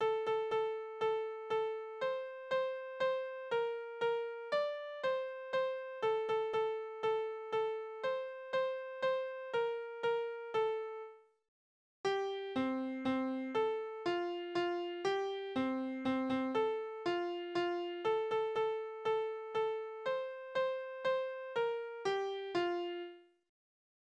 Tanzverse: Polka-Mazurka
Tonart: F-Dur
Taktart: 3/4
Tonumfang: große None
Besetzung: vokal